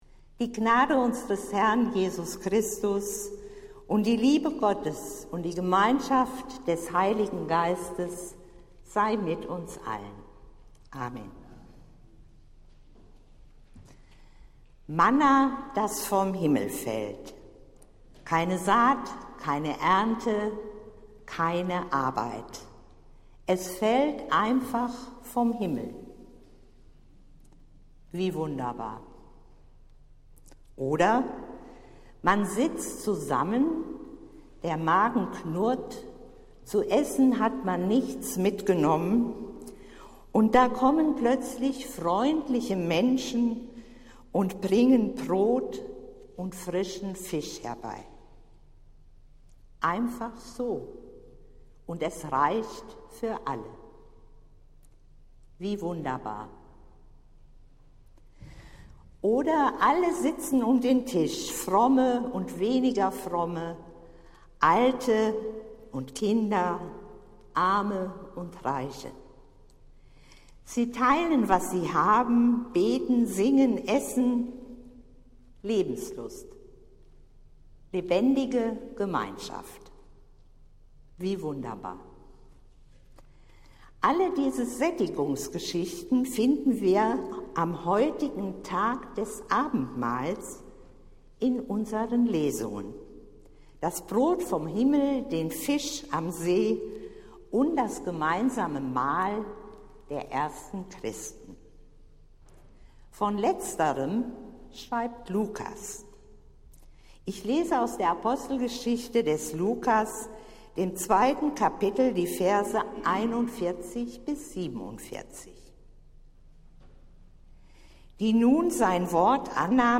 Predigt des Gottesdienstes aus der Zionskirche vom Sonntag, den 23. Juli 2023
Wir haben uns daher in Absprache mit der Zionskirche entschlossen, die Predigten zum Nachhören anzubieten.